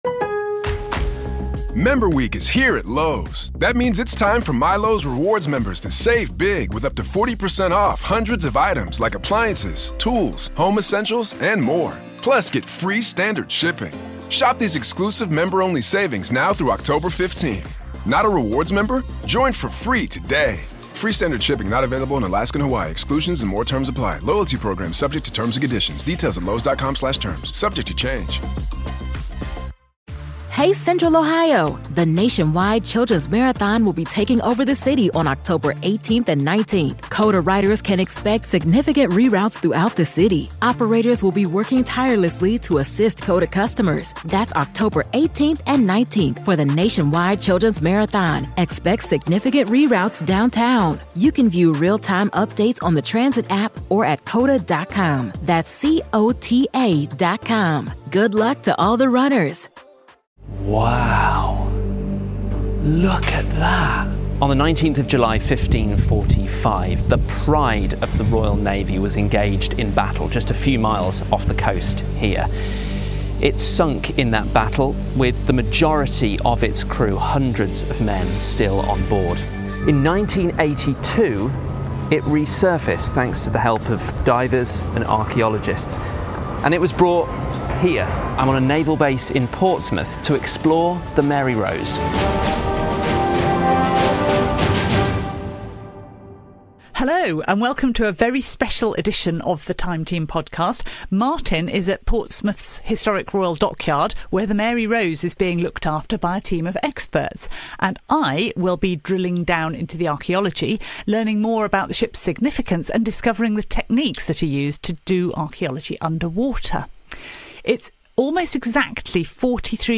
43 years since it rose from the seabed the Time Team podcast has made a special visit to the Mary Rose. Find out how a team of archaeologists and other experts are preserving Henry VIII's flagship at Portsmouth Historic Dockyard.